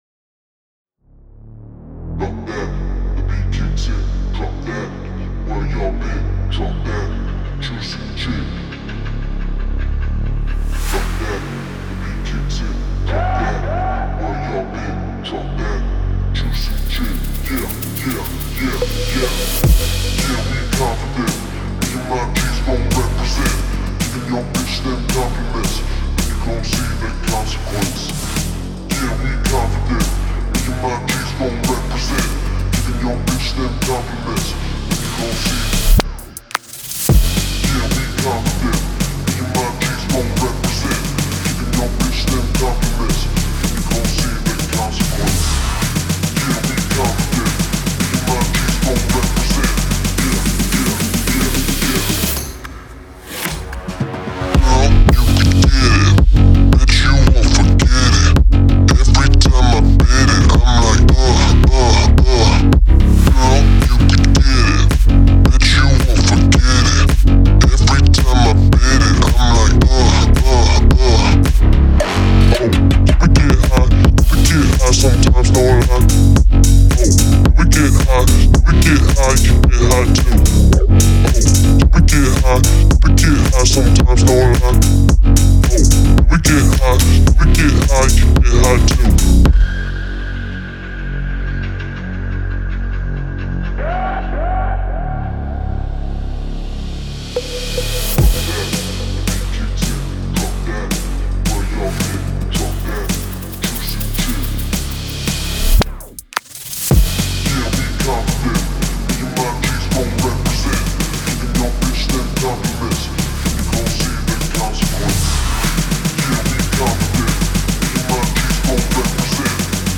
динамичная и энергичная песня